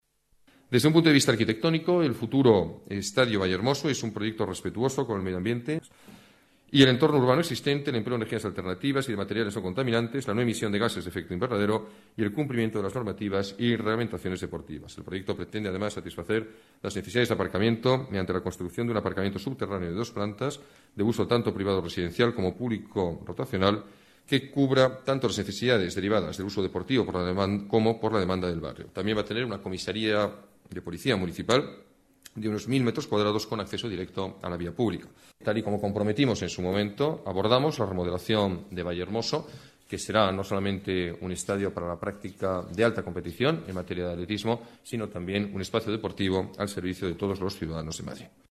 Nueva ventana:Declaraciones del alcalde, Alberto Ruiz-Gallardón: Estadio Vallehermoso